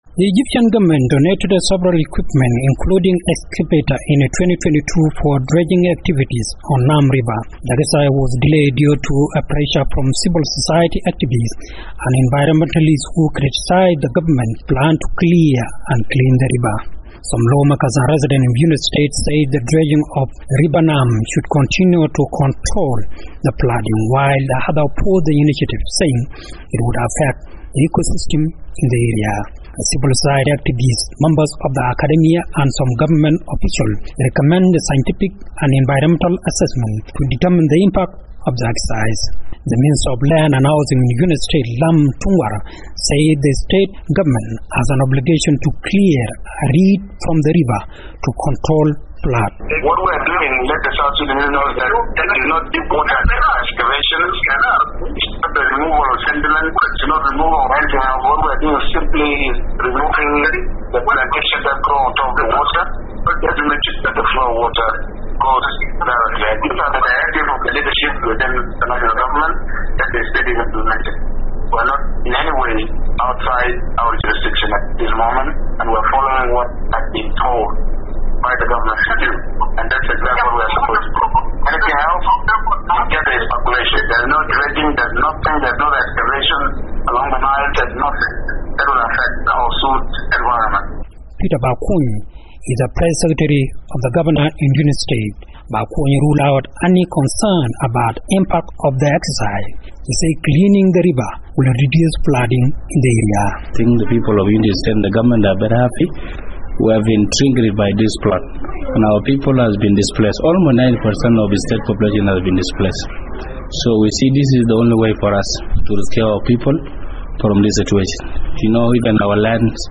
reports from Bentiu, Unity State.